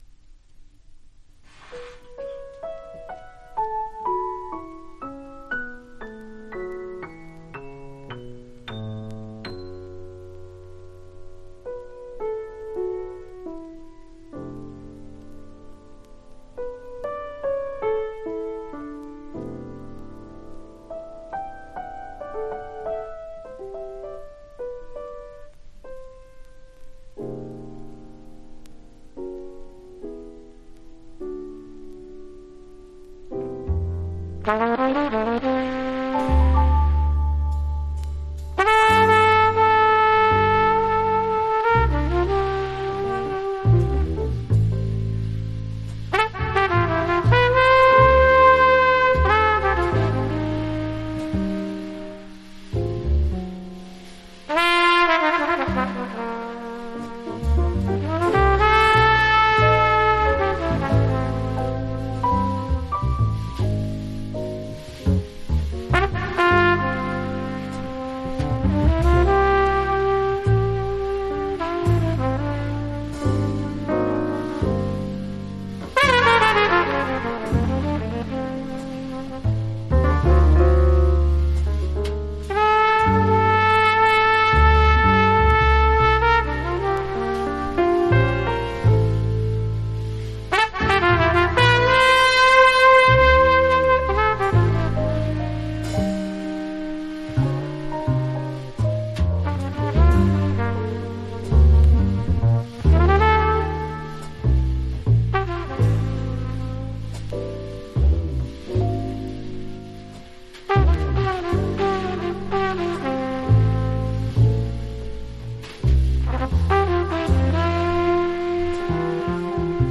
Genre US JAZZ